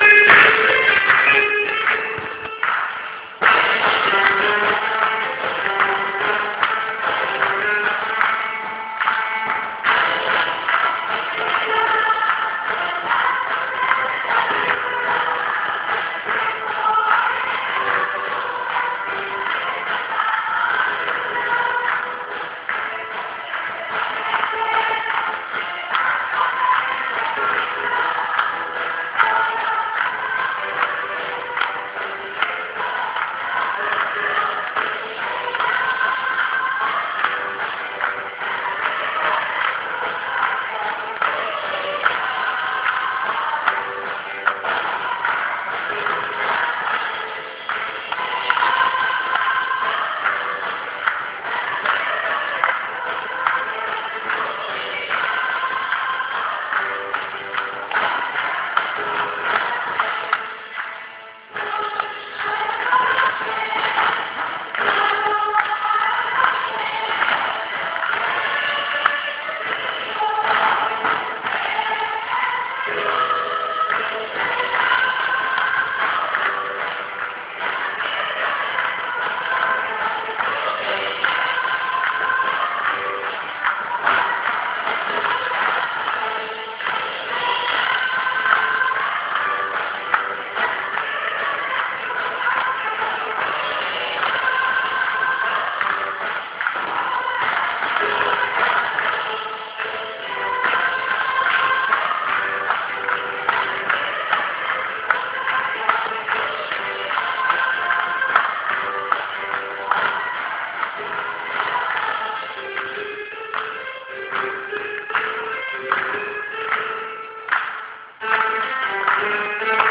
Two of the hymns from the church. The sound quality of the recording isn't great, but it gives a feel for the energy present.